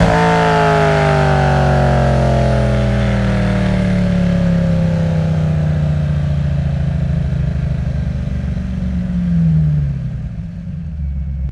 rr3-assets/files/.depot/audio/Vehicles/v8_04/v8_04_Decel.wav
v8_04_Decel.wav